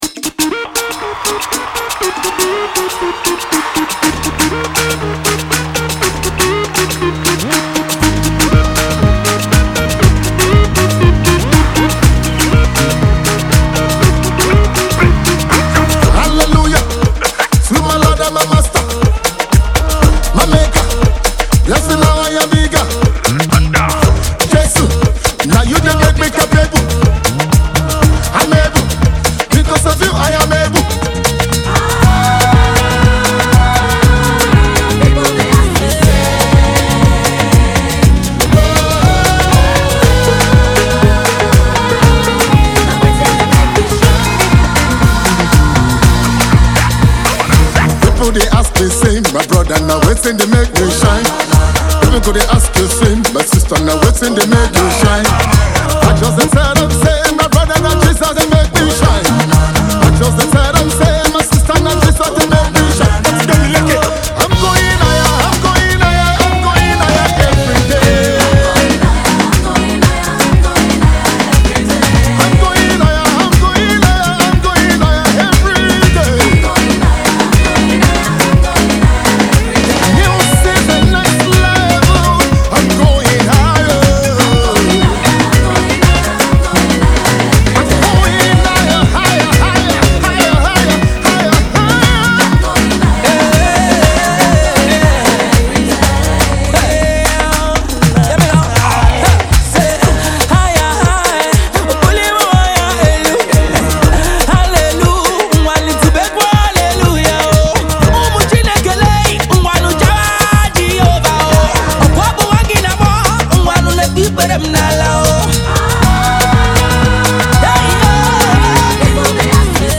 Highlife Meets Afrobeat
urban talented gospel artiste
highly-groovy uptempo